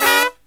FALL HIT08-R.wav